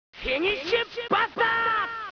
The top table has music and the one below it has sound effects
24KB This is one of Trunks doing one of his attacks.